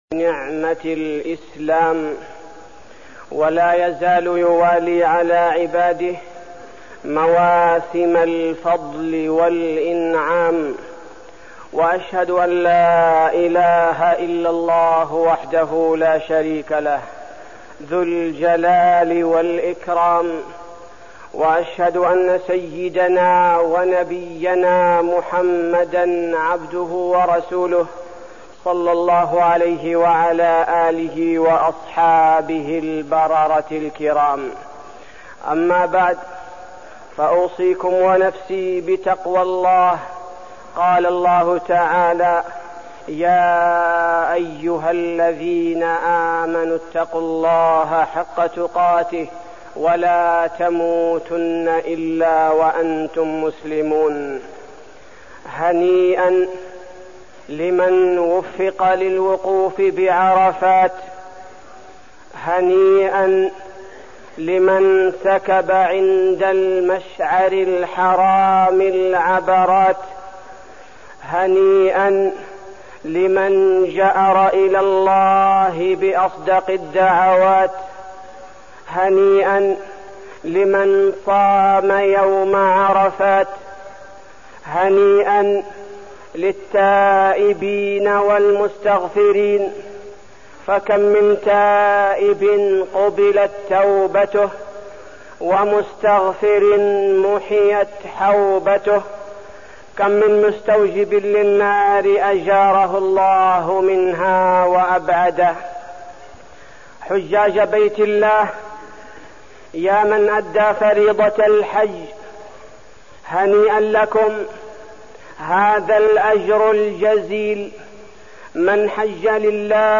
تاريخ النشر ١٣ ذو الحجة ١٤١٨ هـ المكان: المسجد النبوي الشيخ: فضيلة الشيخ عبدالباري الثبيتي فضيلة الشيخ عبدالباري الثبيتي الأعمال الصالحة بعد الحج